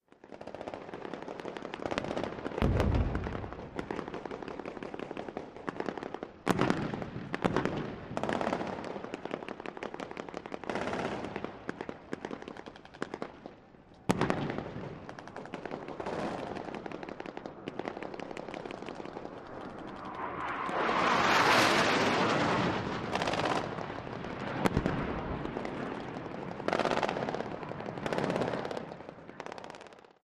Battle simulation with rapid fire weapons and jet and helicopter flybys. Weapons, Gunfire Bombs, War Battle, Military